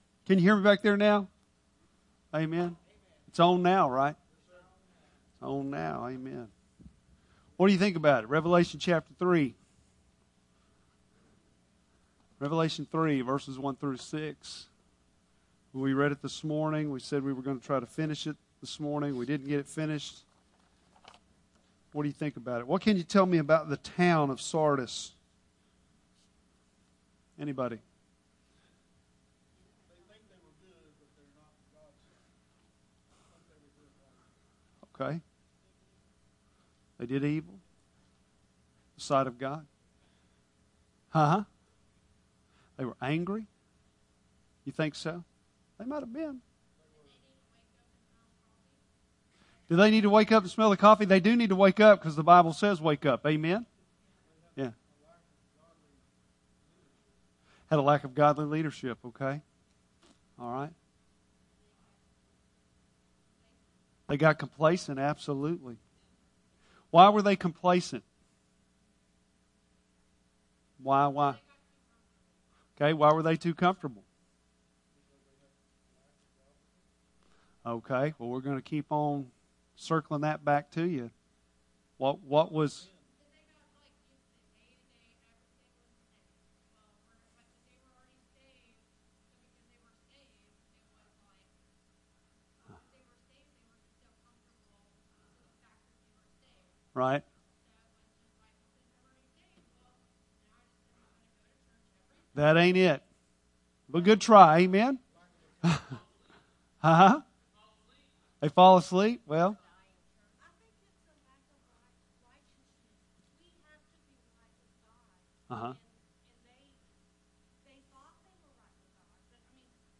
Bible Text: Revelation 3:1-6 | Preacher